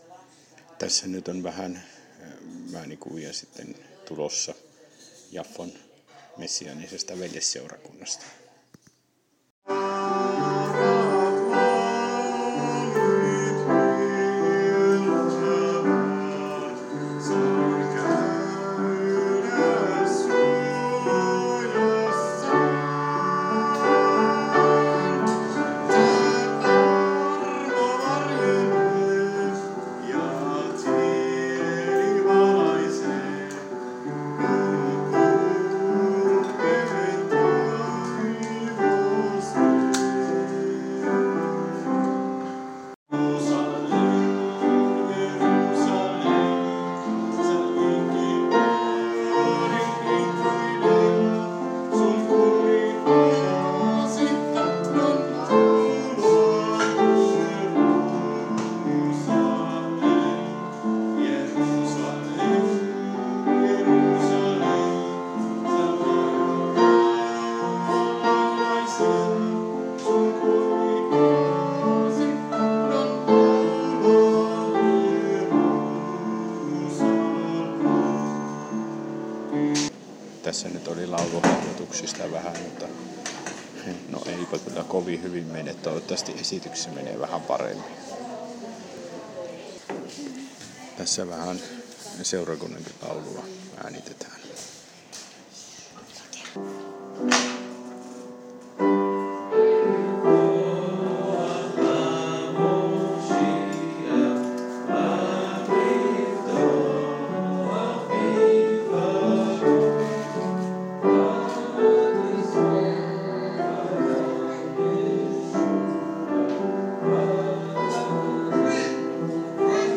Ääniä mm. vanhasta Jaffasta